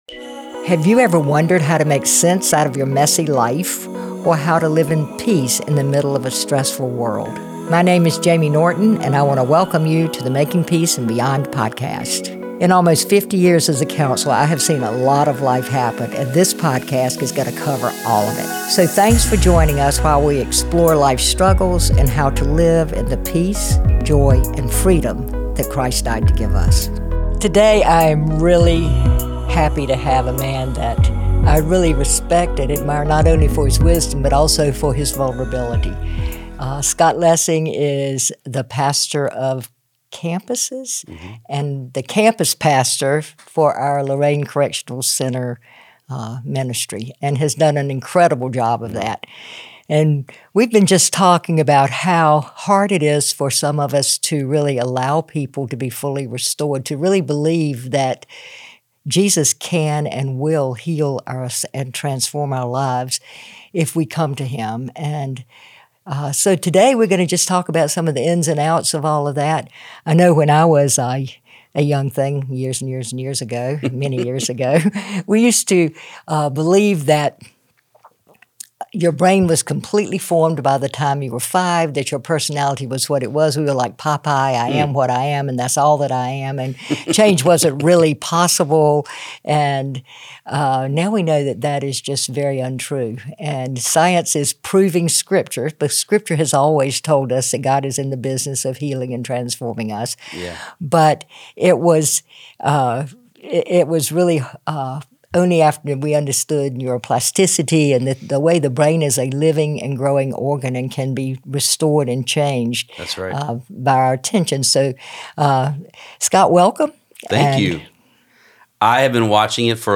They discuss the power of vulnerability, breaking free from shame, and how God’s healing work reshapes our identity. From overcoming addiction to learning to trust again, this conversation dives deep into the challenges and victories of spiritual restoration.